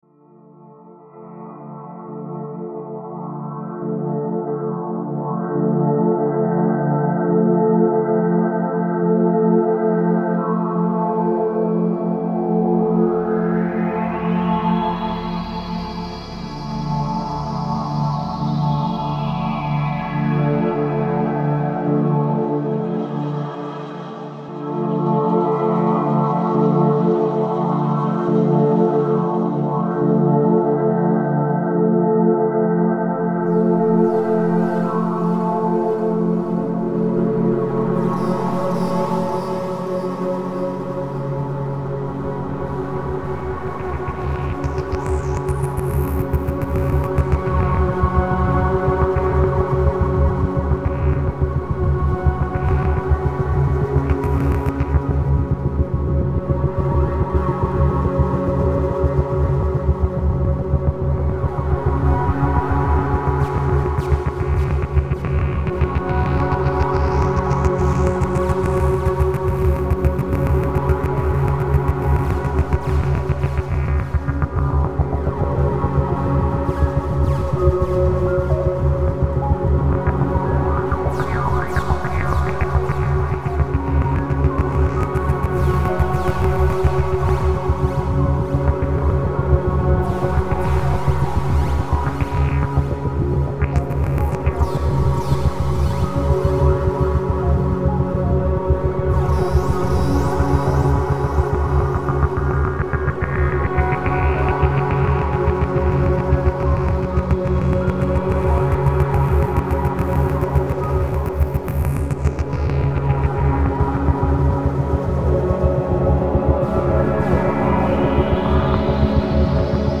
Cinématique...